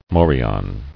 [mo·ri·on]